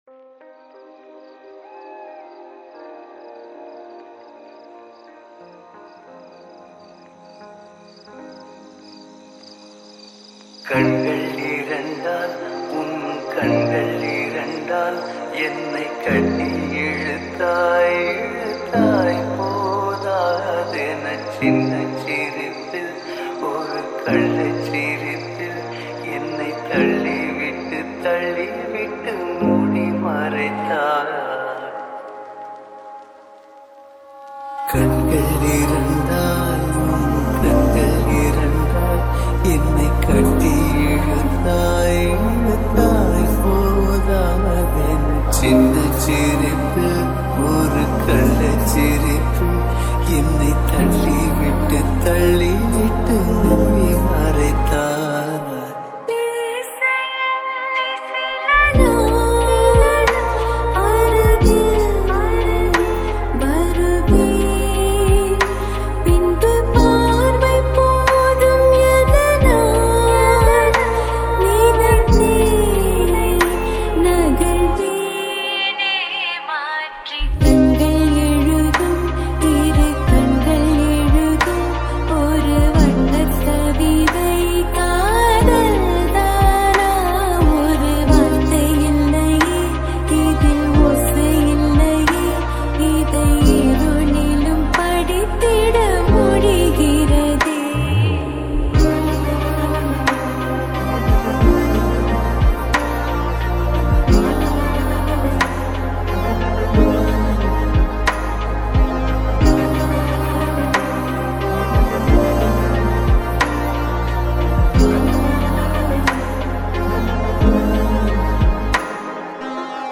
All TAMIL LOFI REMIX